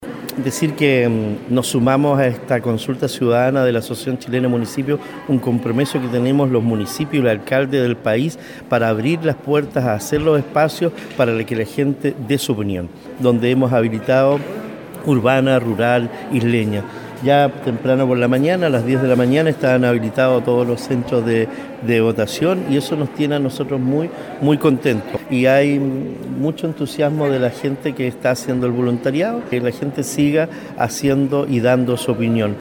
16-ALCALDE-QUELLON.mp3